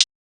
Closed Hats
TS HiHat_5.wav